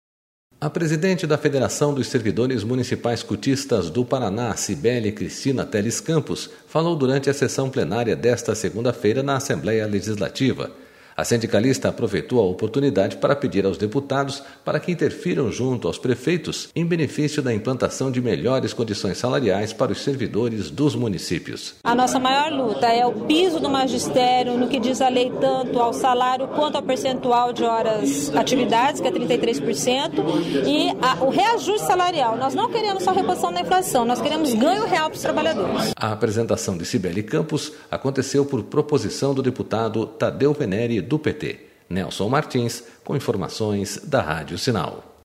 Sindicalistas falam aos deputados pedindo melhores condições de trabalho para os servidores municipais